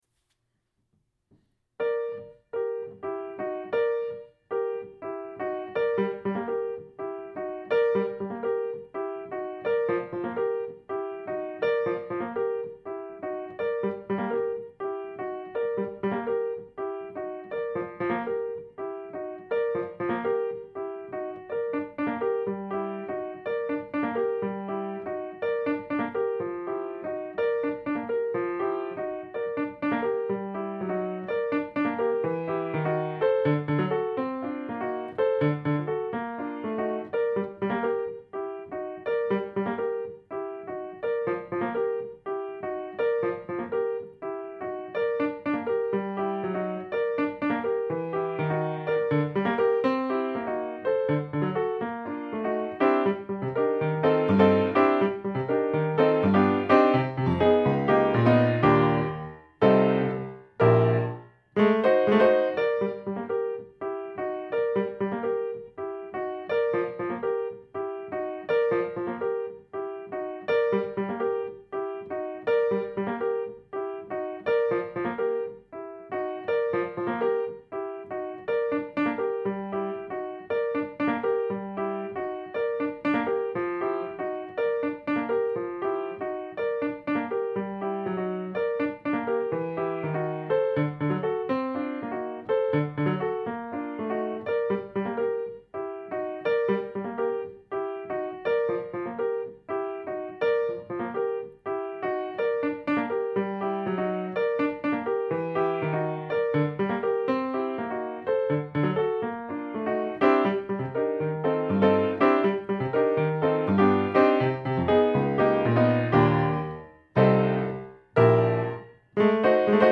Ostinautical intermezzi for solo piano